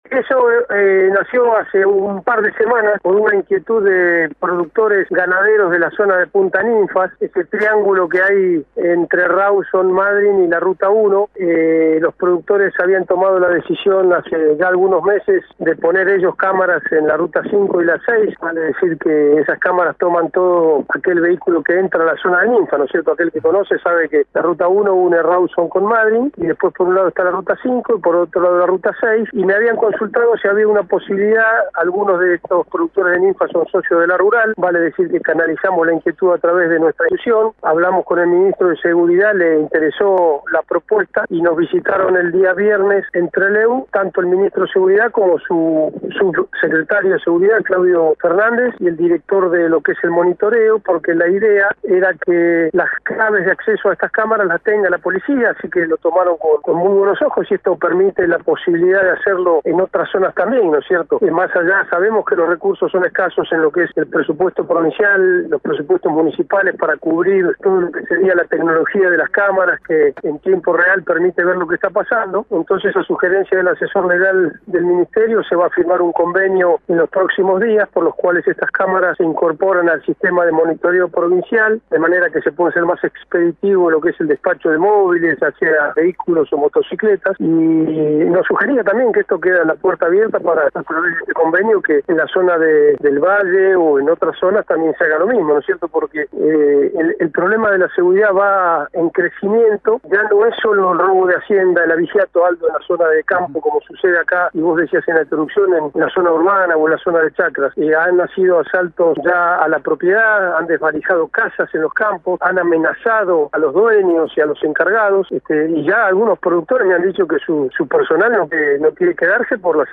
La entrevista fue derivando hacia el sentimiento de inseguridad y la falta de respuestas desde la justicia.